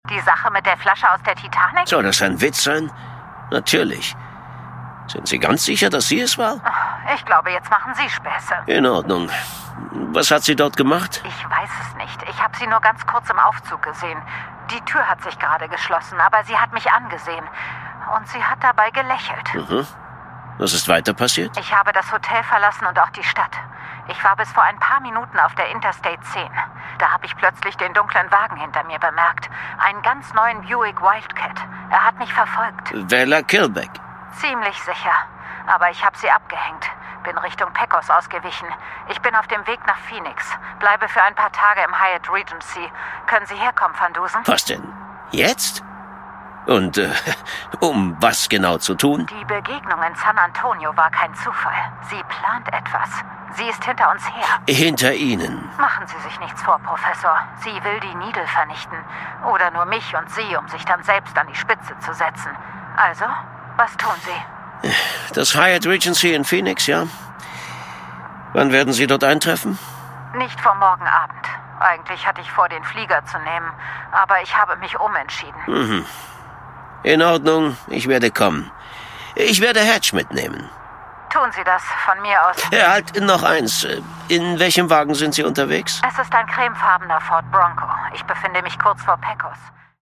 Produkttyp: Hörspiel-Download